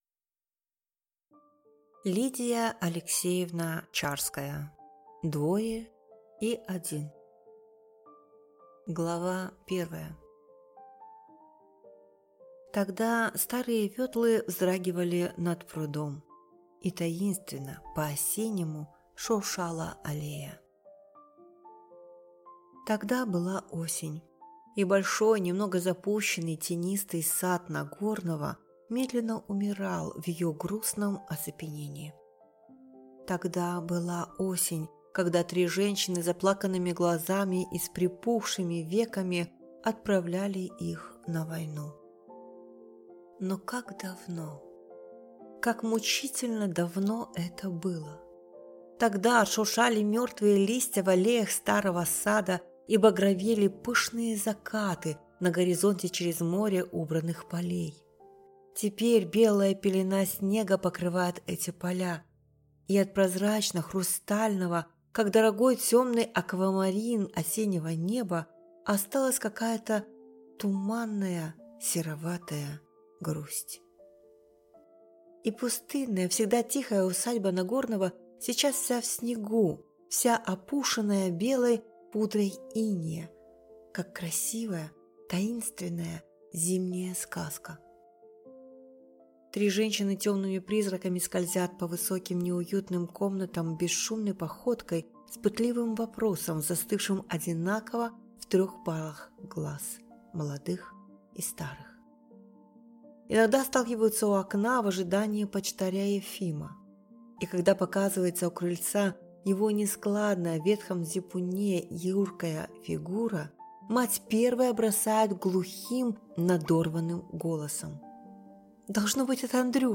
Аудиокнига Двое и один | Библиотека аудиокниг